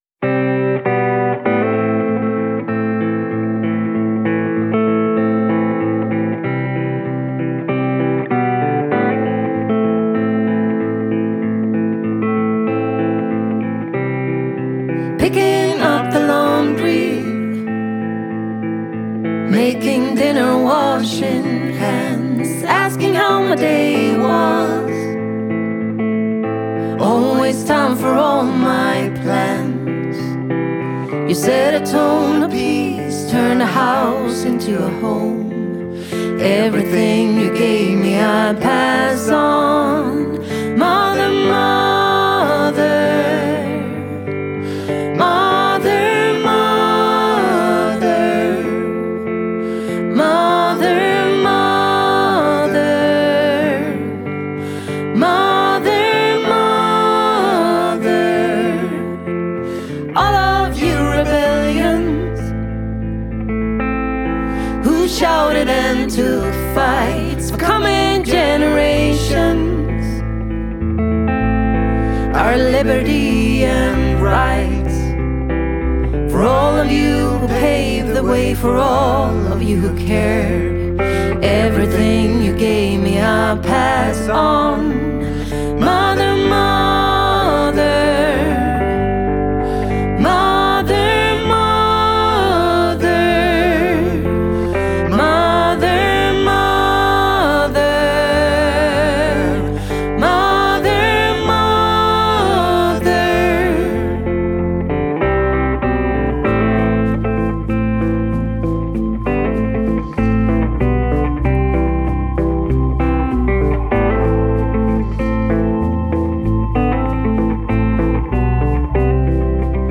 tre röster till en gemensam ton – lågmäld, men stark.
Genre: Skandicana